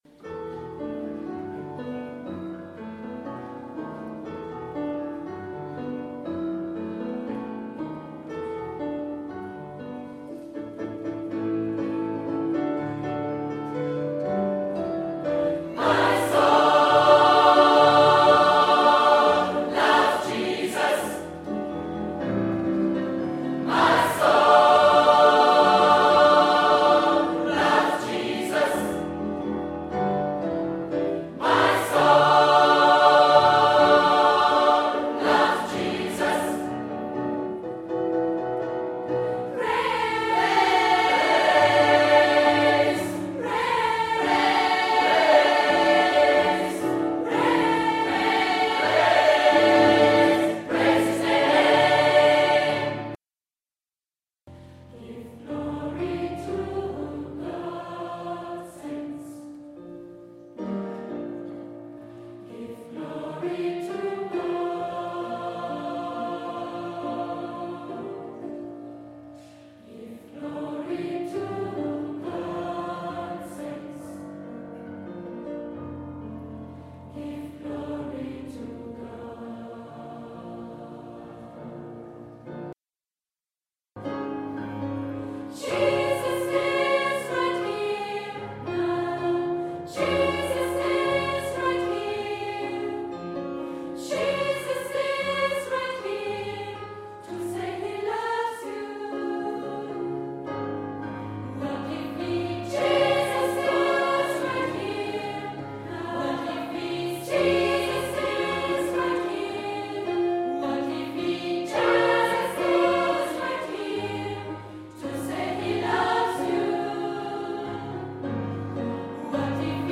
Der Chor der Pfarre Peuerbach organisierte einen Gospelworkshop.
Chorsingen
Insgesamt 50 TeilnehmerInnen folgten unserem Aufruf und ließen sich auf dieses spannende Chorerlebnis ein.